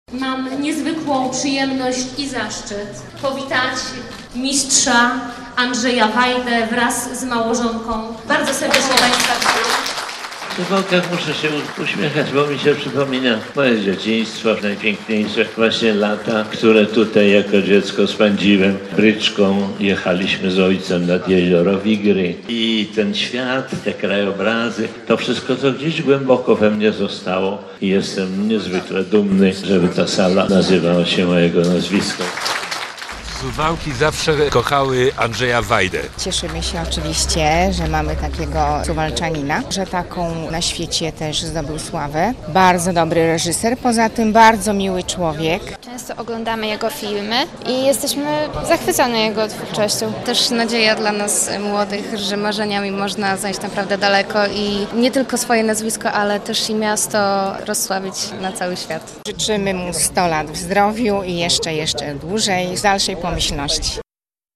Andrzej Wajda odwiedził Suwaki - na urodziny "dostał" salę koncertową - relacja